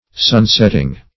Sunset \Sun"set"\, Sunsetting \Sun"set`ting\, n.